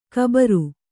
♪ kabaru